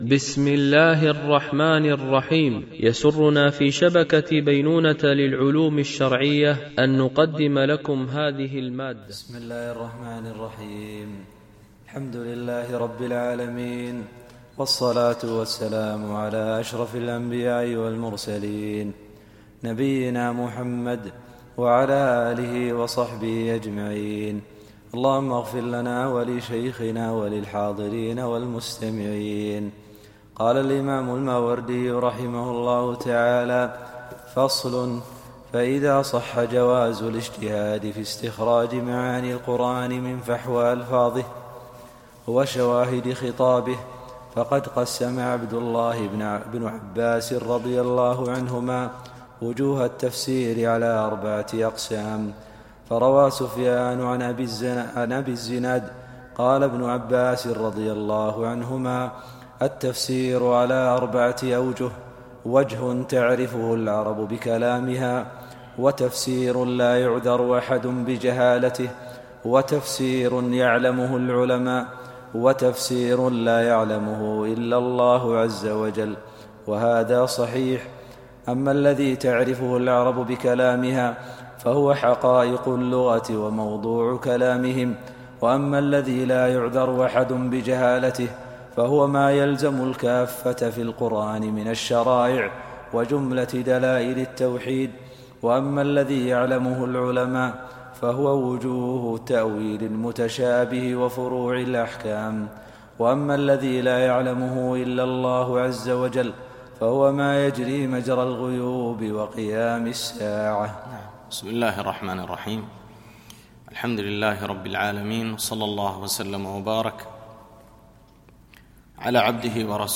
شرح مقدمة الماوردي في تفسيره النكت والعيون ـ الدرس 05